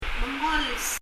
mengol　　　[məŋʌl]　　　運ぶ　　carry
発音